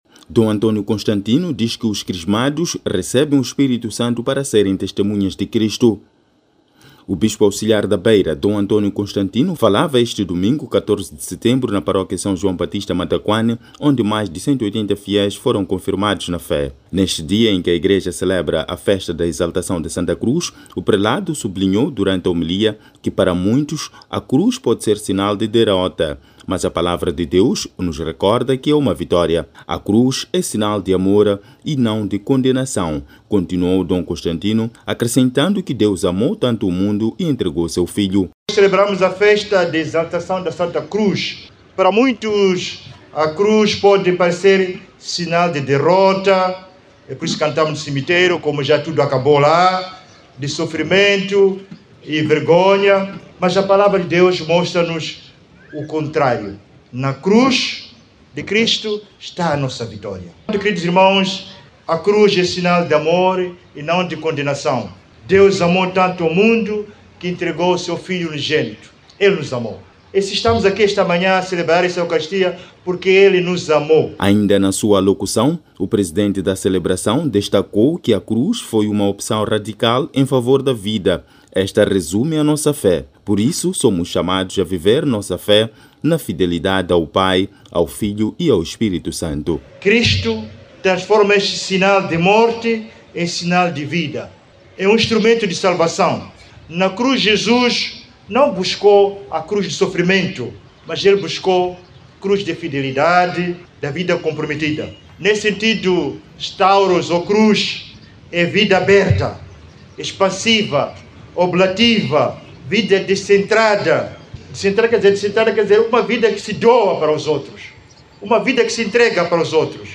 O Bispo Auxiliar da Beira, Dom António Constantino, falava este domingo, 14 de Setembro na Paróquia São João Baptista – Matacuane, onde mais de 180 fiéis foram conformados na fé.